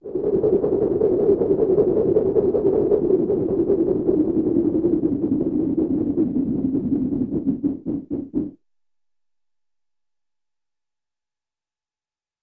RapidLowSpinFlap CRT042205
Steady, Rapid, Low Pitched Spin Or Insect-like Wing Flaps